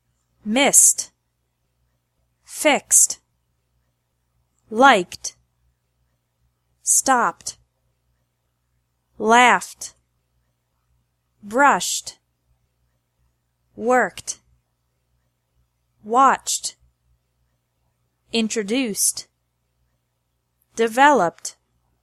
2) -ED is pronounced like T (NO extra syllable)
After verbs ending in S, X, K, P, F, SH, and CH sounds